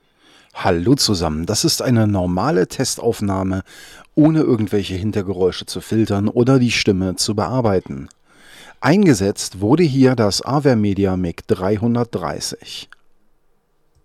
Hier eine Standard Aufnahme ohne Bearbeitung und Ohne Filterung, rein wie das Signal reinkommt:
Vor allem jedoch wirkt das Avermedia MIC 330 wesentlich wärmer und angenehmer, als das NEAT King Bee 2, hier bemerkt man klar das ein hoher Preis nicht unbedingt Zielführend sein muss denn das Avermedia MIC 330 ist auf Stimme ausgelegt. Keinerlei Hintergrundgeräusche werden störend mit aufgenommen, wobei es schon sehr ruhig war als die Aufnahmen gemacht worden sind.
Avermedia-MIC-330-ohne-Bearbeitung.mp3